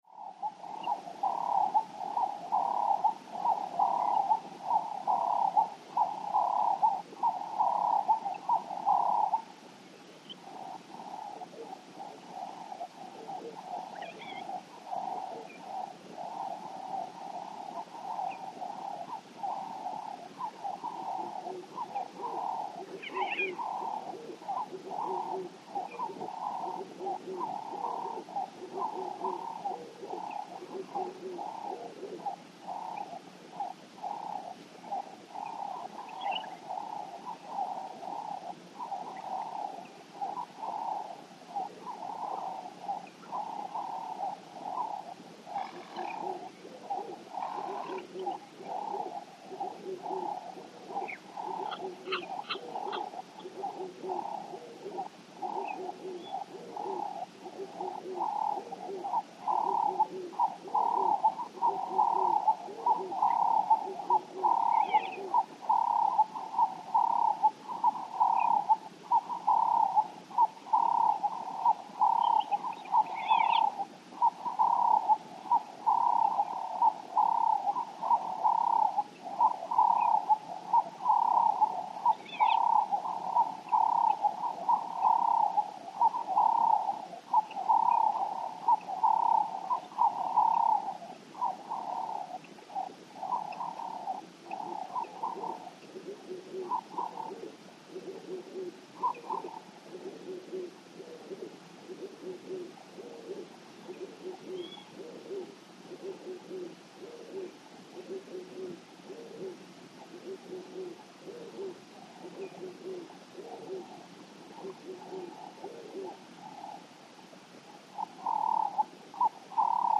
ANIMALS-BIRD BGS AFRICA: Dawn Ibis calls, distant insects, song bird calls, light water lapping, Kibuye, Rwanda. Lake Kivu atmosphere.